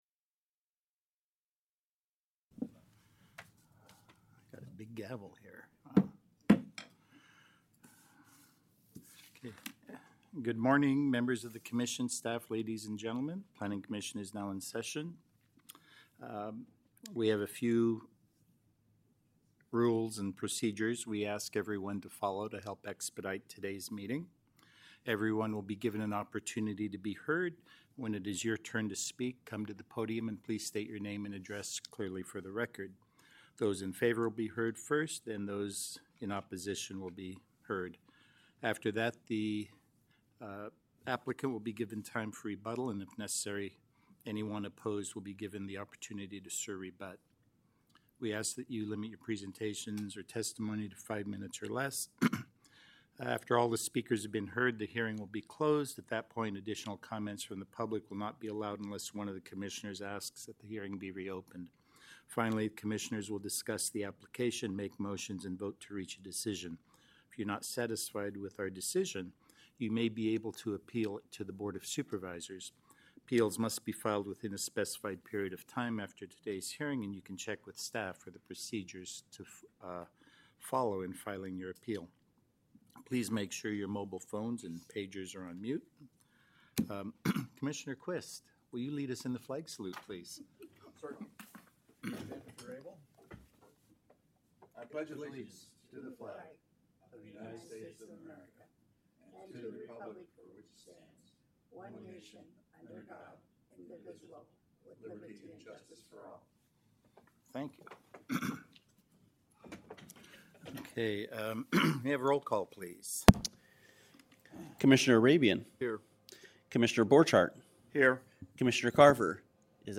March 27, 2025 Fresno County Planning Commission Hearing